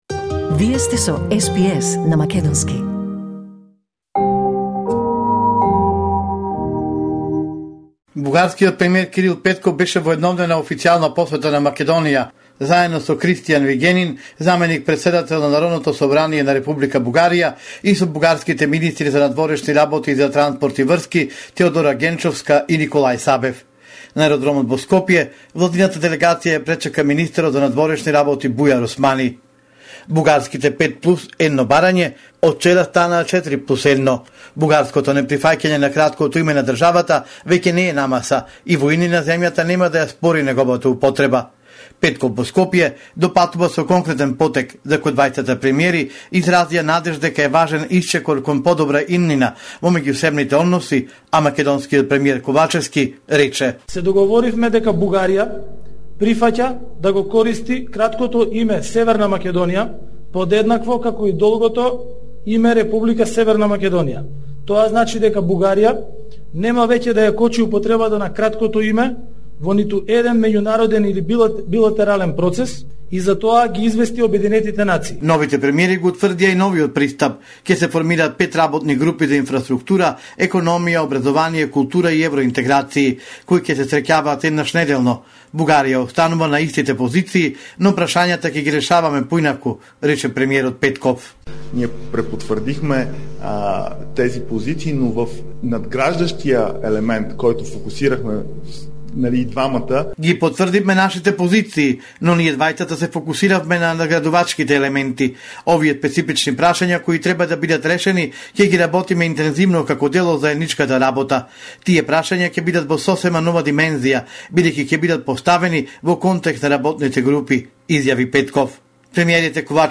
Homeland Report in Macedonian 19 January 2022